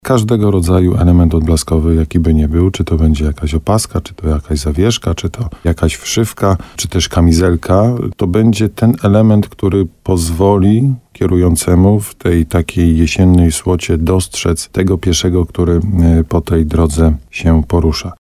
Dla swojego bezpieczeństwa powinniśmy nosić odblaski także na terenach, gdzie nie brakuje lamp ulicznych – mówi policjant.